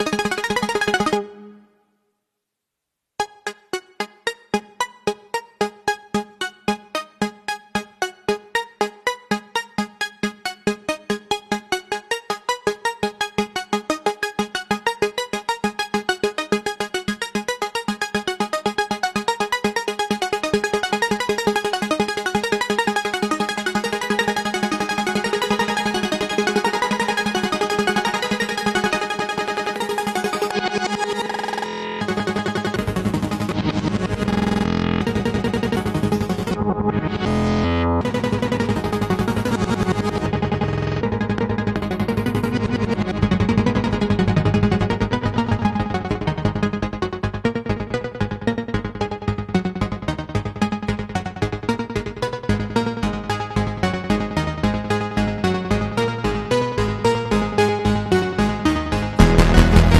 The synth performance that started